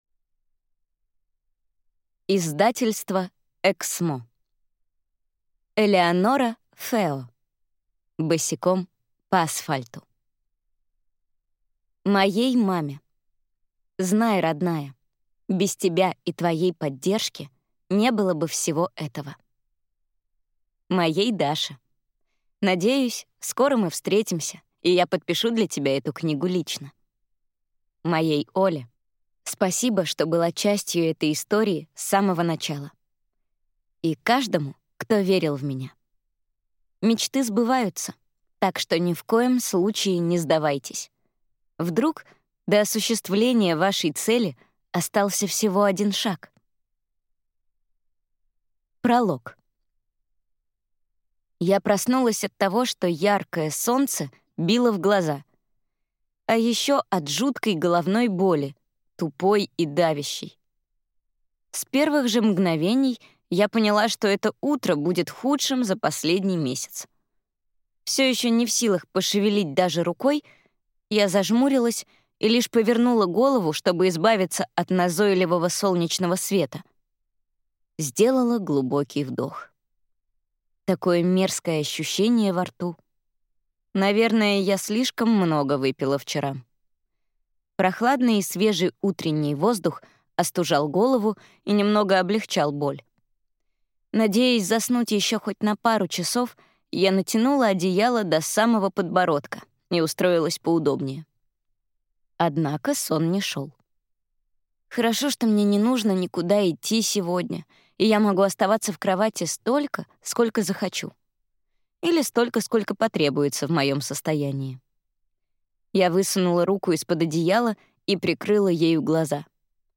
Аудиокнига Босиком по асфальту | Библиотека аудиокниг